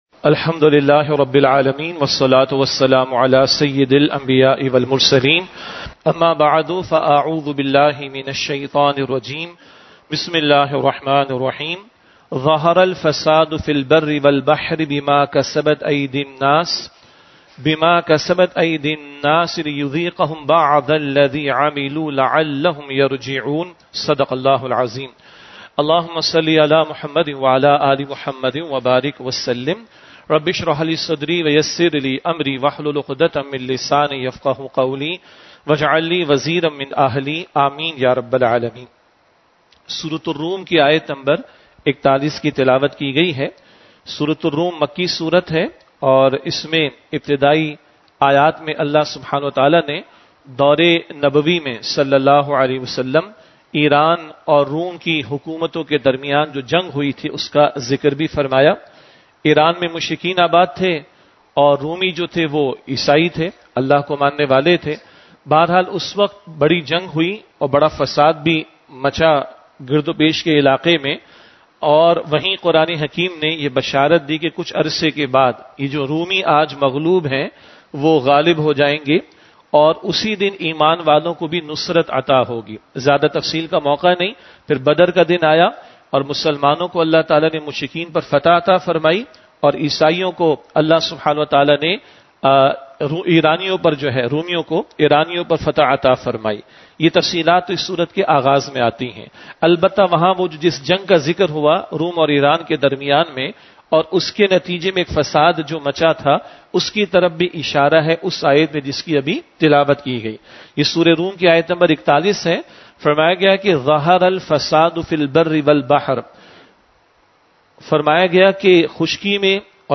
Khutbat-e-Jummah (Friday Sermons)
Masjid Jame Al-Quran, Quran Academy Lahore. Blasphemy Messengership Sacraments Heinous conspiracy and Apathy!